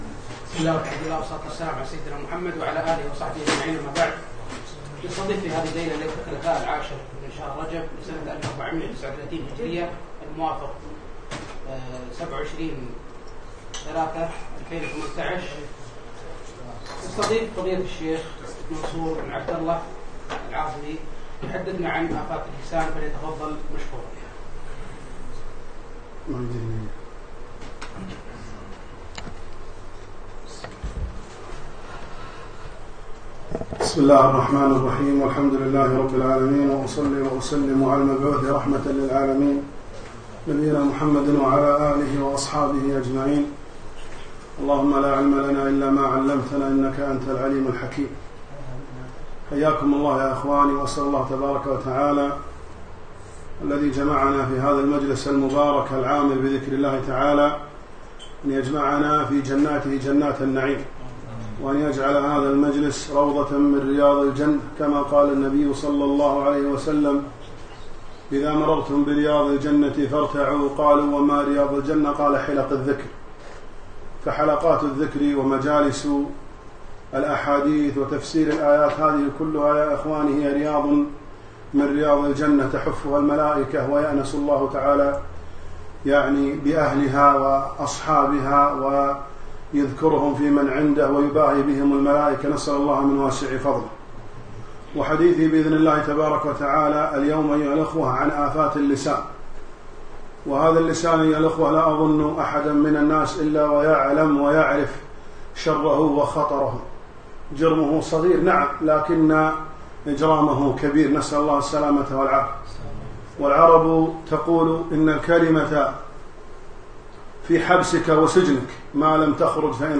محاضرة - آفات اللسان